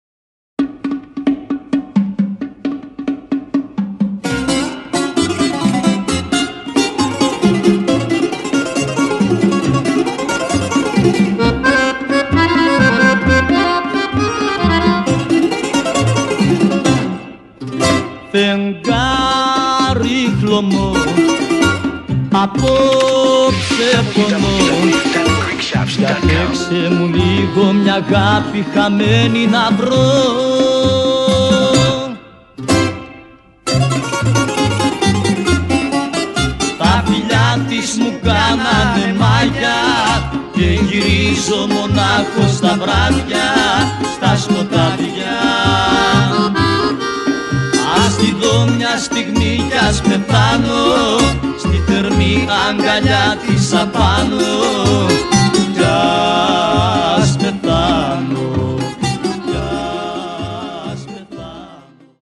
a 2CD set featuring today's modern Greek love songs.